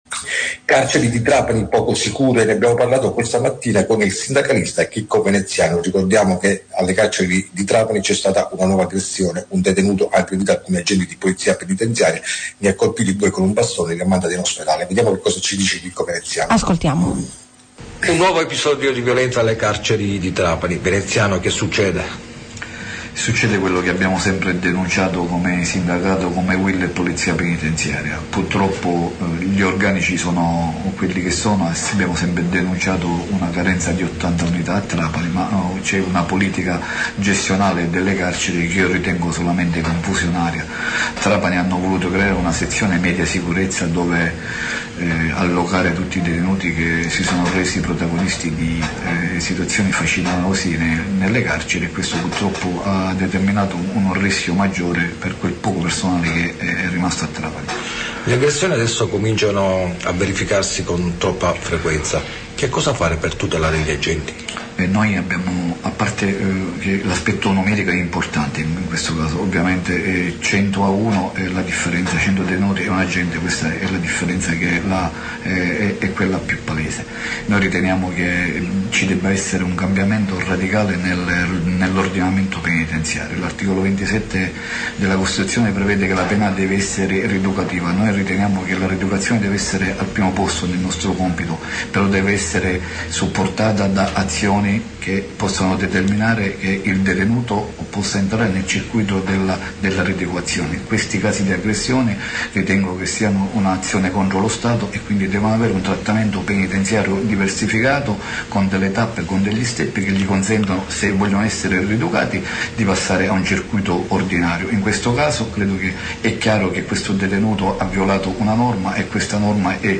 AGGRESSIONE A DUE POLIZIOTTI PENITENZIARI AL CARCERE DI TRAPANI: INTERVISTA